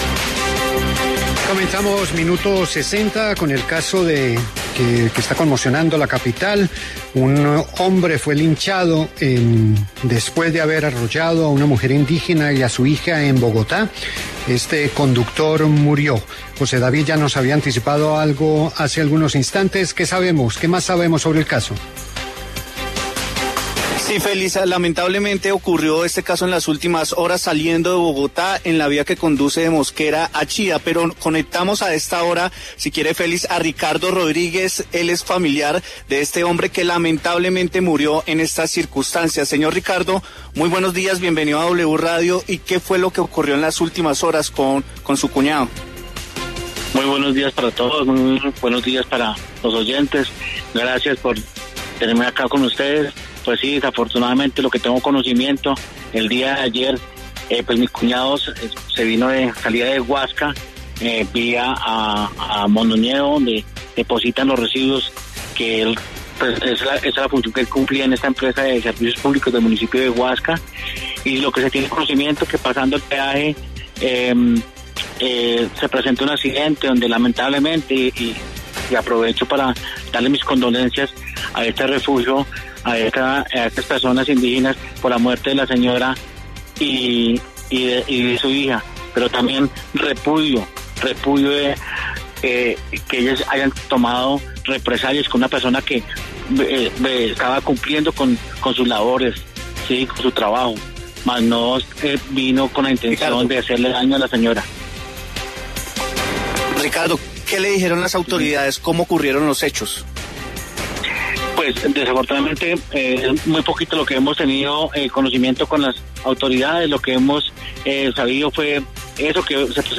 En diálogo con W Radio